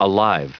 Prononciation du mot alive en anglais (fichier audio)
Prononciation du mot : alive